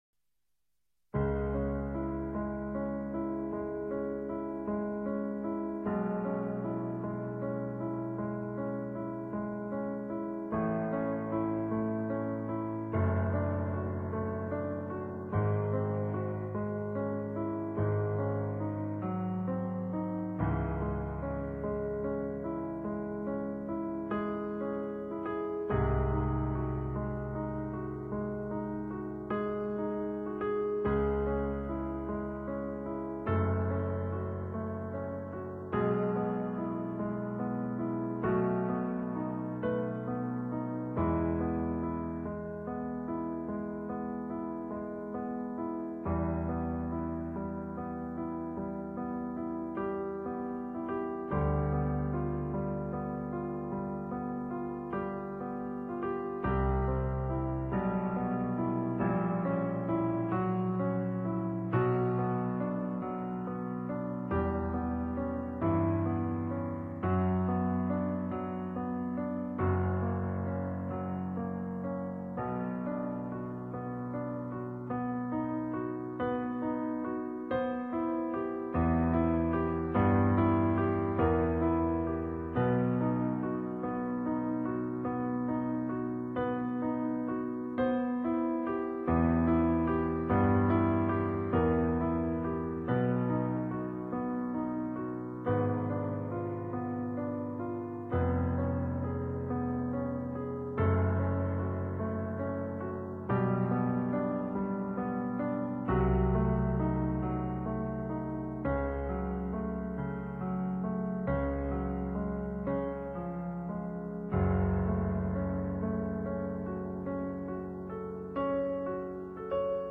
Música: Moonlight Sonata – Beethoven